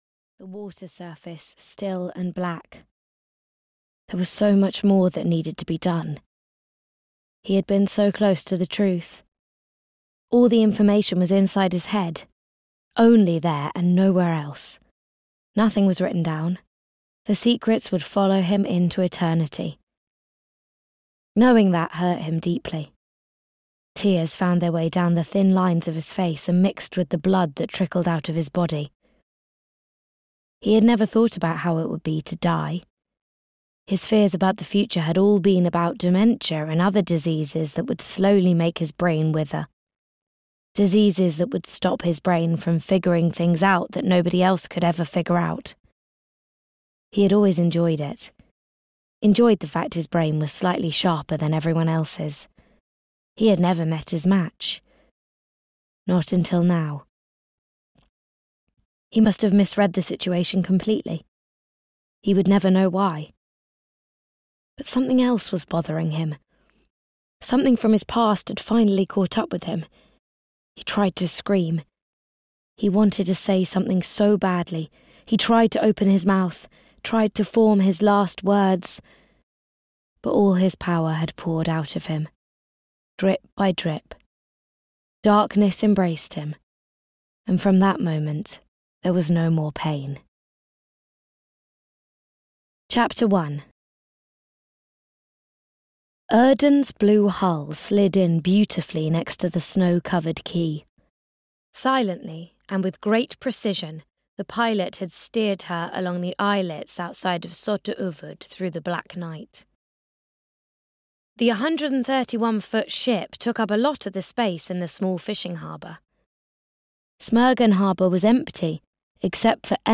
Audiobook The Ice Fisher, written by Anna Ihren.
Ukázka z knihy